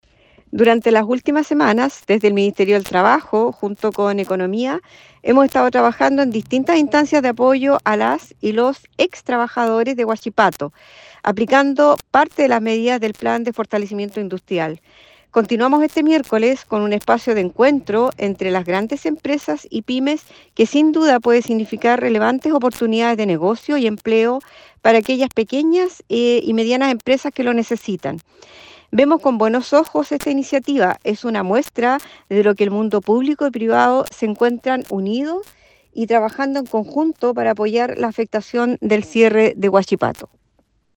Por su parte, la seremi del Trabajo y Previsión Social, Sandra Quintana, valoró la colaboración entre entidades públicas y privadas a la hora de enfrentar la afectación provocada por el cierre de la siderúrgica.